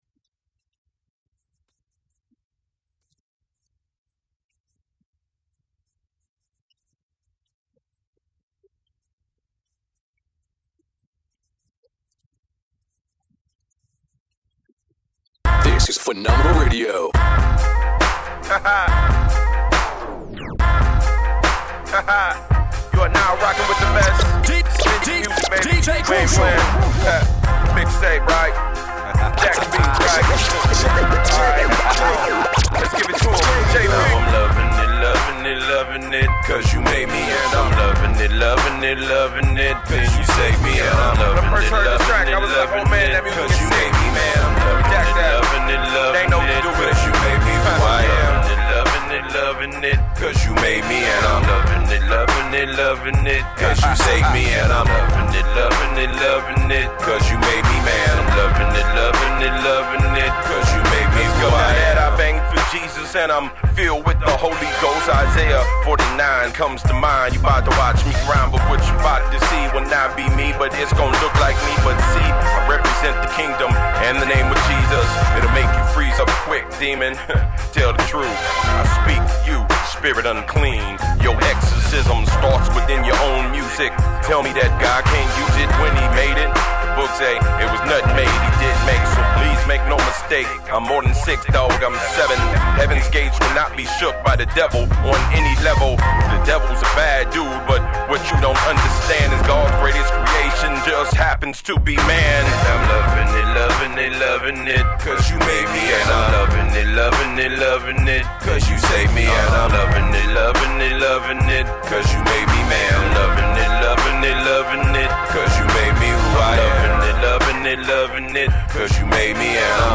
Description : A much Needed Dose of Hip Hop, Funk AND Soul.